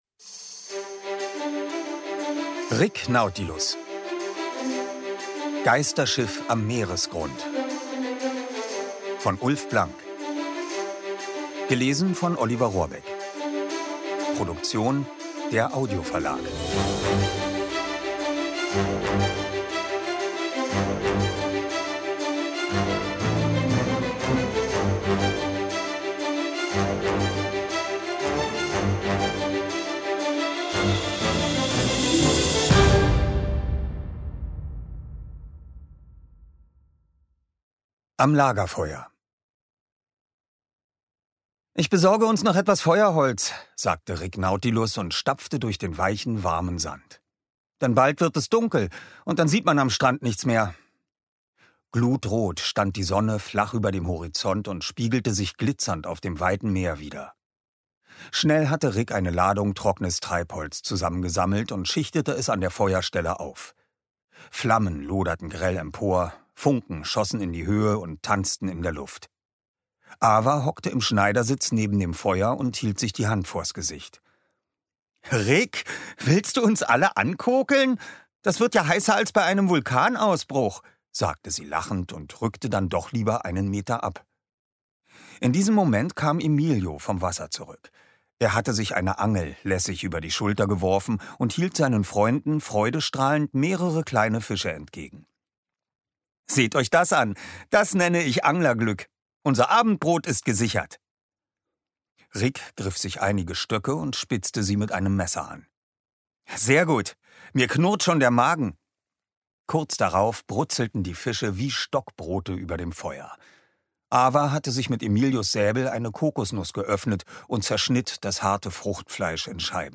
Lese- und Medienproben
Rick Nautilus – Teil 4: Geisterschiff am Meeresgrund Ungekürzte Lesung mit Musik
Oliver Rohrbeck (Sprecher)